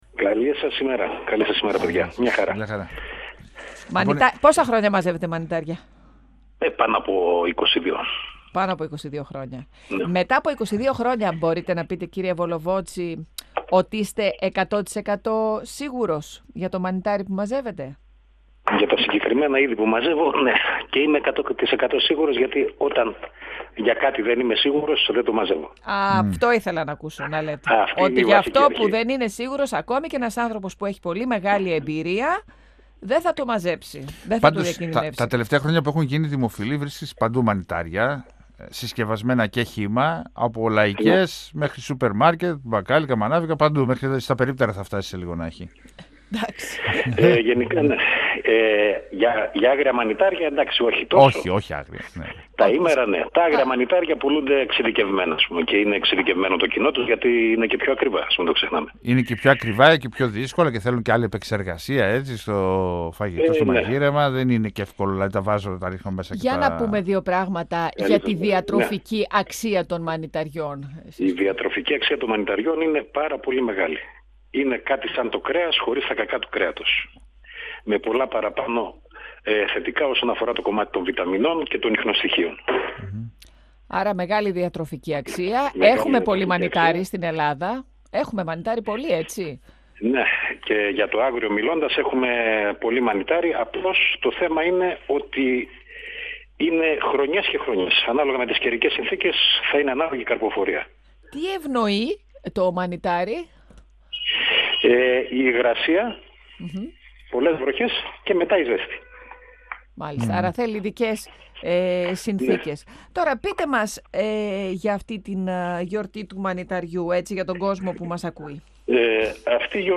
στον 102FM του Ρ.Σ.Μ. της ΕΡΤ3